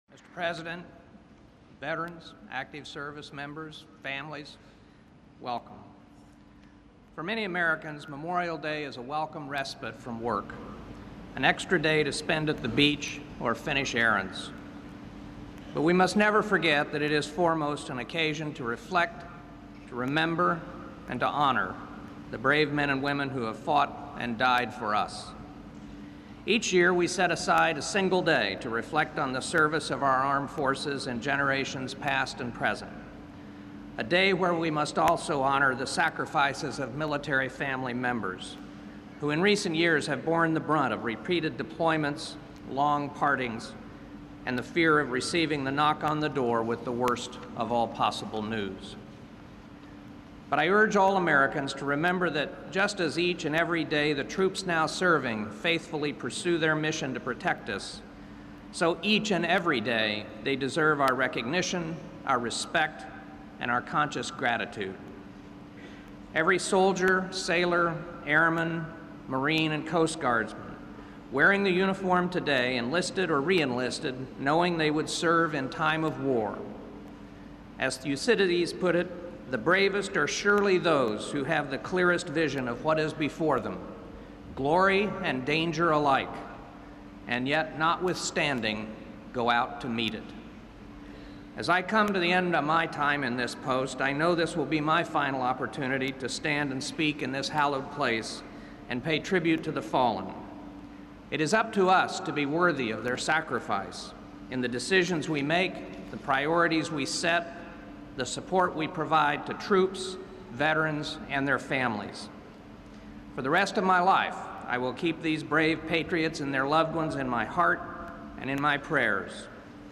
U.S. President Barack Obama delivers a Memorial Day address at the Tomb of the Unknowns in Arlington National Cemetery
Obama recounts a number of examples of friendship and sacrifice by U.S. troops in Iraq, Afghanistan and around the world. He is introduced by Secretary of Defense Robert M. Gates.
Recorded at the Tomb of the Unknowns in Arlington National Cemetery, VA, May 30, 2011.